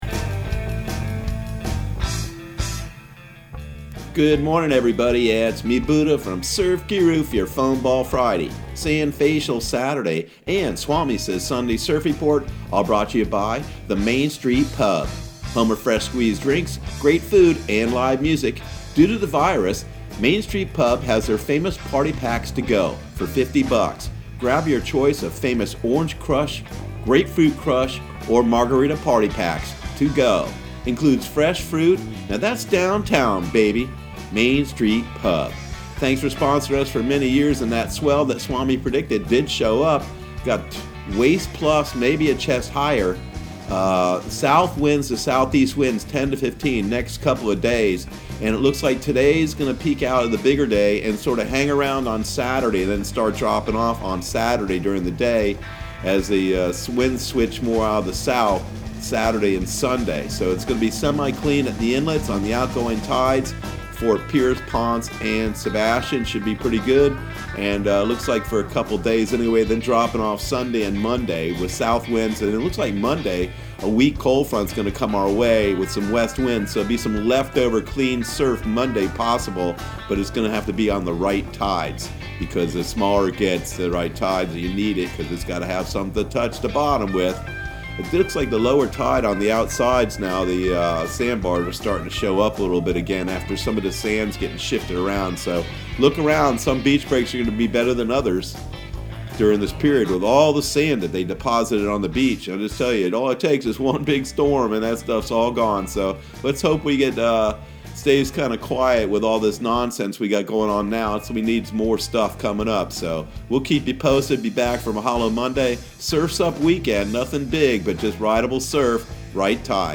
Surf Guru Surf Report and Forecast 03/27/2020 Audio surf report and surf forecast on March 27 for Central Florida and the Southeast.